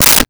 Switch 07
Switch 07.wav